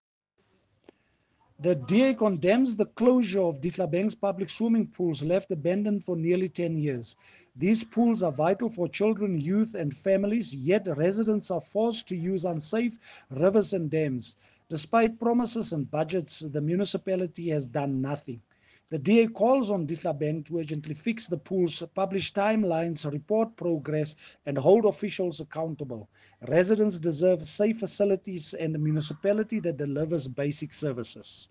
Afrikaans soundbites by Cllr Marius Marais and